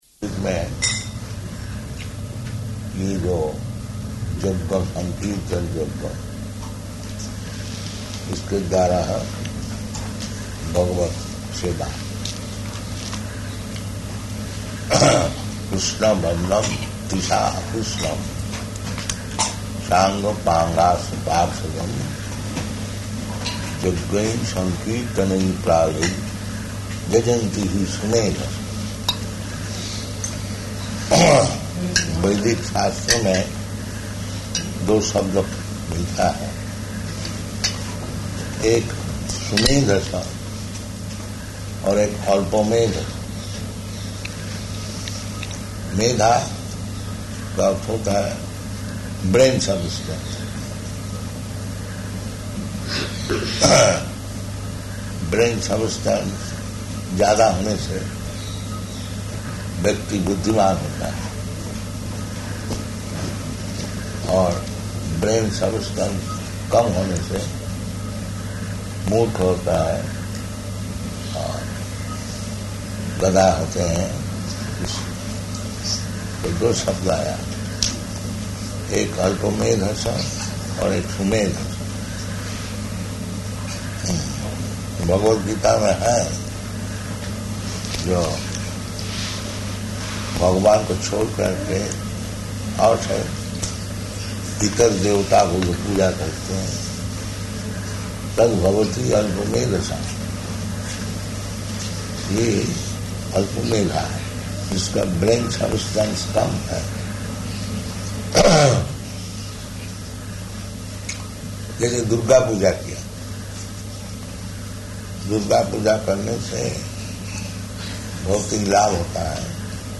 Lecture in Hindi
Type: Lectures and Addresses
Location: Aligarh